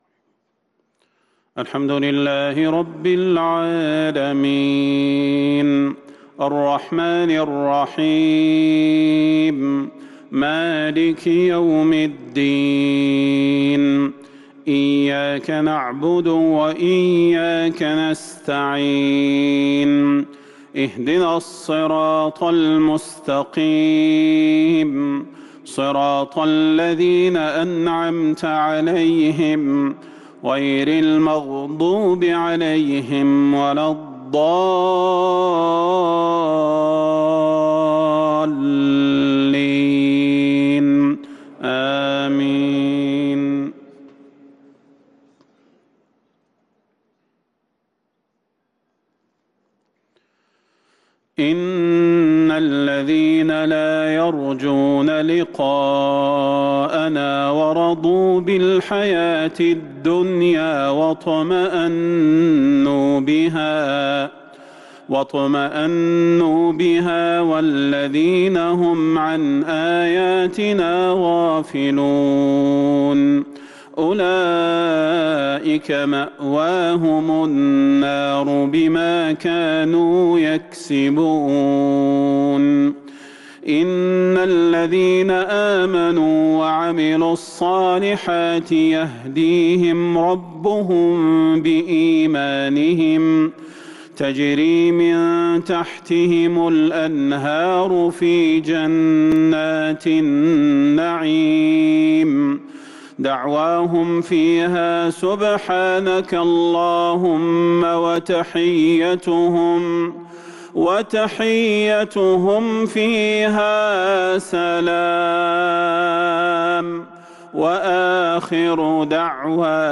مغرب الأحد 8-5-1443هـ من سورة يونس | Maghrib prayer from Surah Yunus 12/12/2021 > 1443 🕌 > الفروض - تلاوات الحرمين